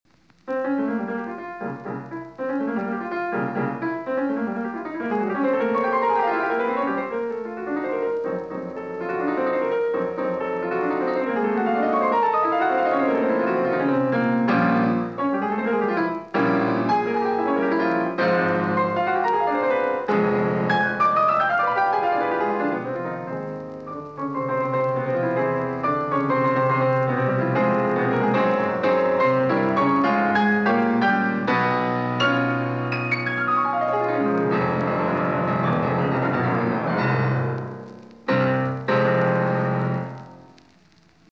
The analyzed Chopin performances were audio recordings played by 5 famous pianists:
Artur Rubinstein recorded in 1946